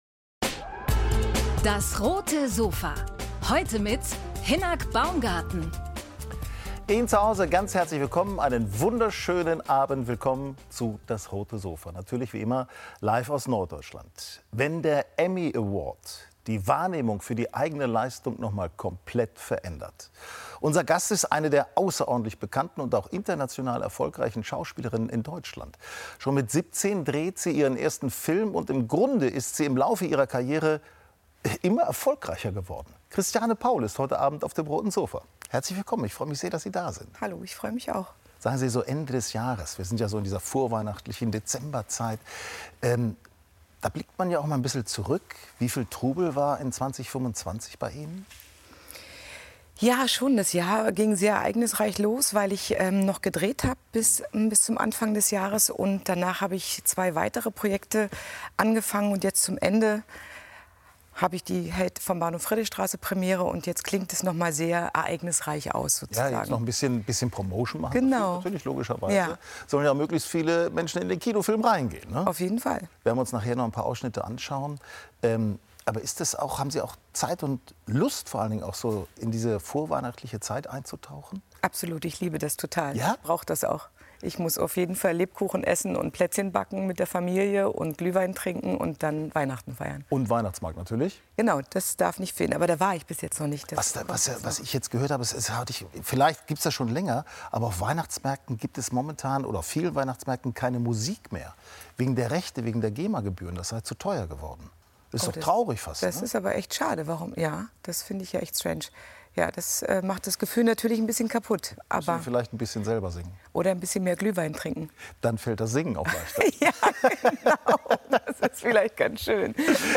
DAS! - täglich ein Interview